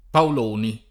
[ paol 1 ni ]